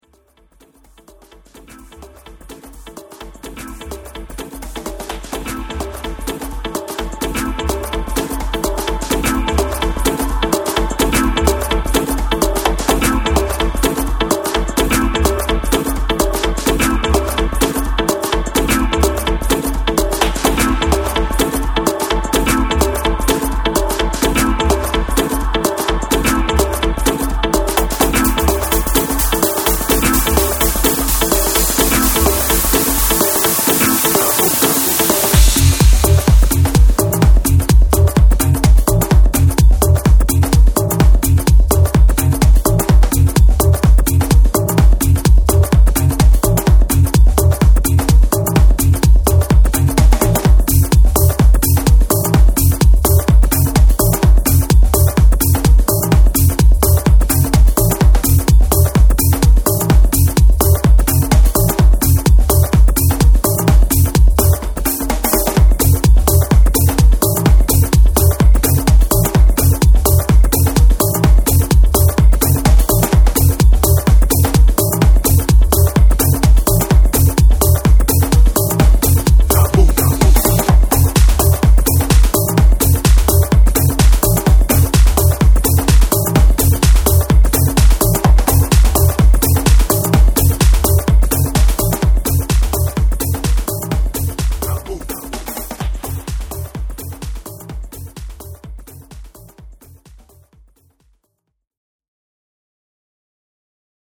Styl: House, Techno, Minimal